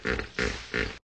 hippo.ogg